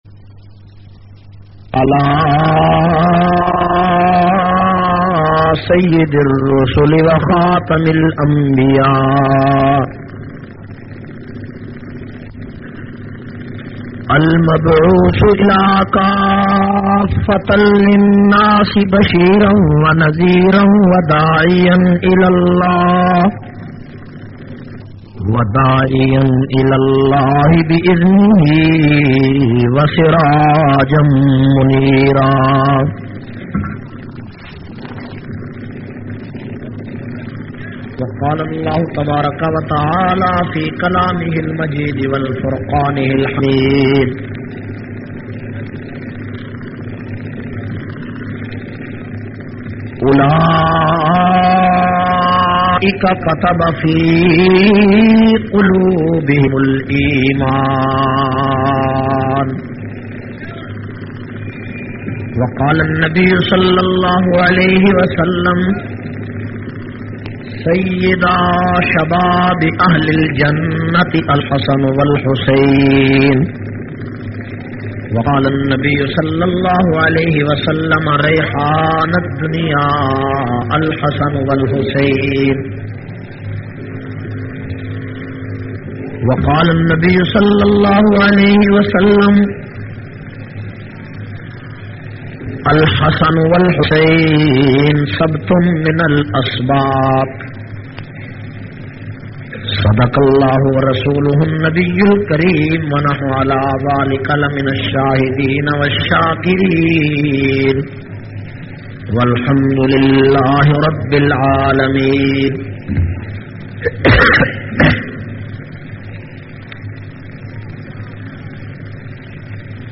210- Tareekh e Ahle bait Namak Mandi peshawar 09 muharram al haram bayan.mp3